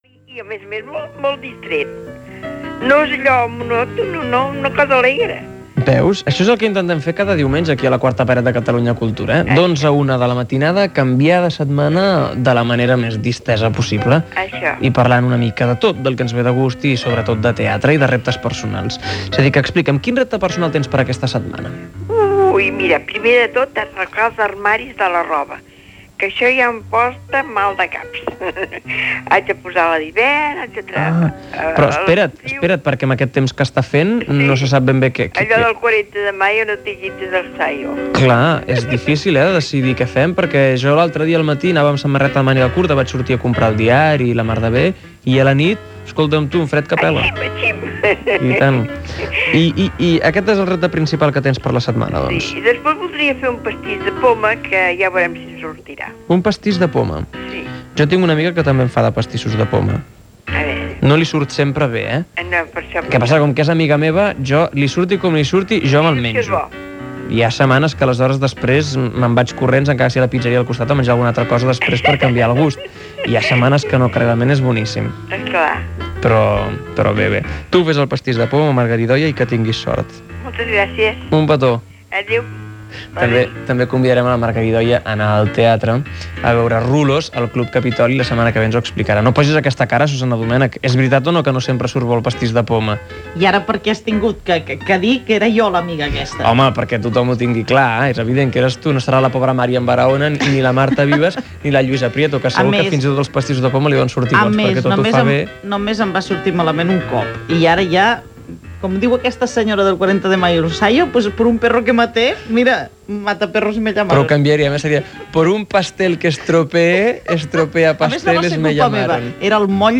Trucada telefònica sobre reptes personals i presentació d'un tema musical.